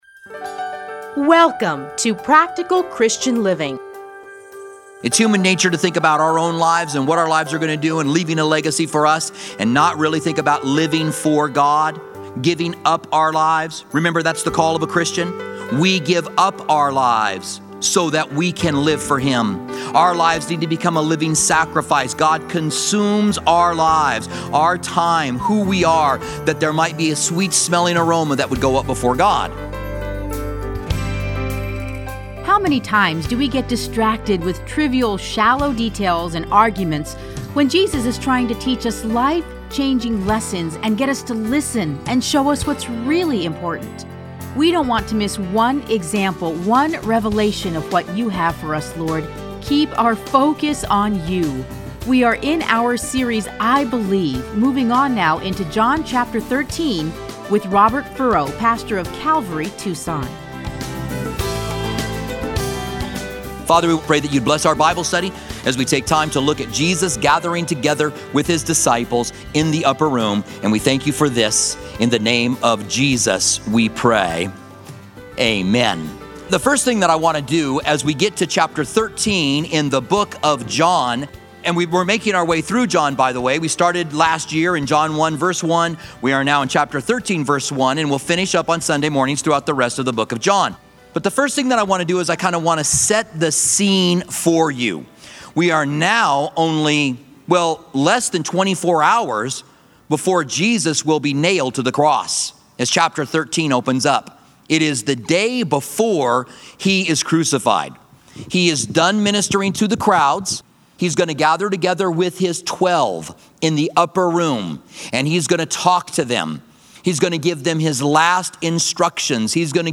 Listen to a teaching from John 13:1-10.